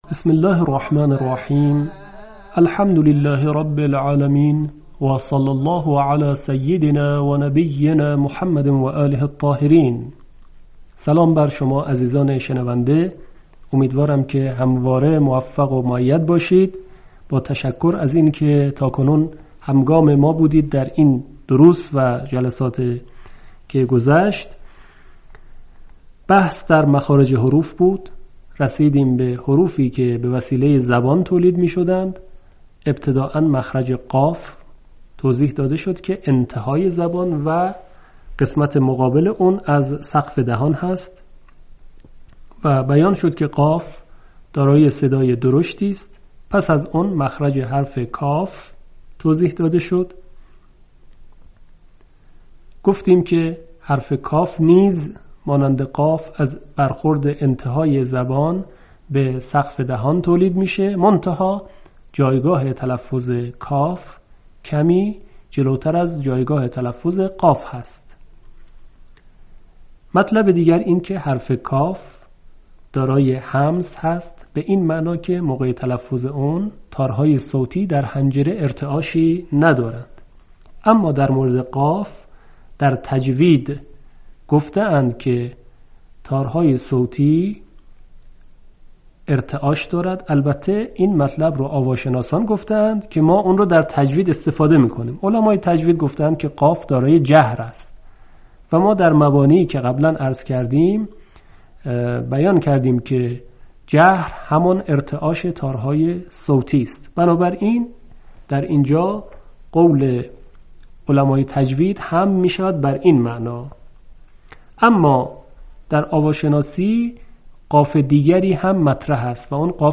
آموزش تجوید تحقیقی